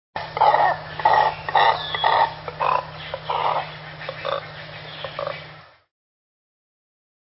Bird Sound
Deep guttural grunts.
Double-crestedCormorant.mp3